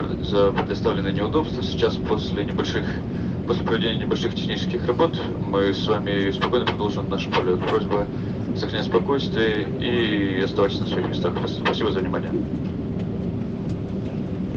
самолет